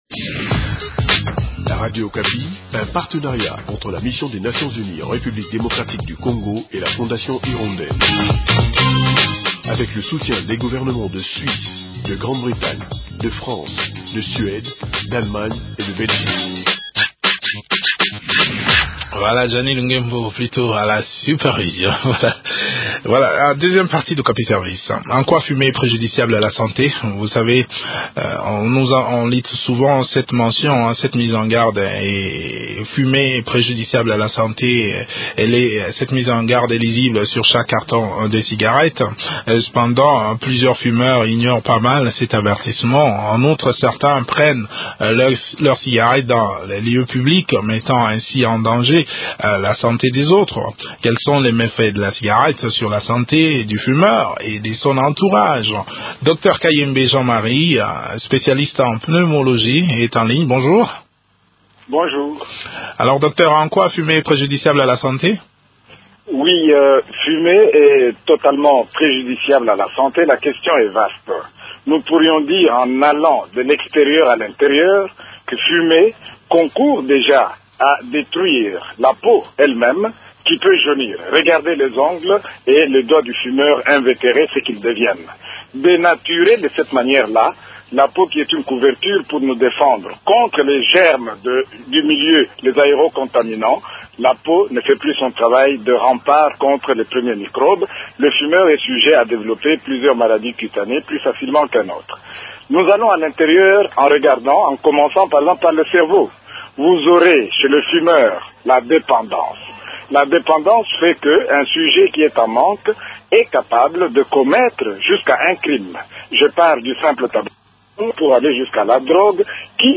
Ils fument à longueur des journées sans se soucier des conséquences que cela peut entrainer. A quel danger on s’expose en fumant ? Eléments des réponses dans cet entretien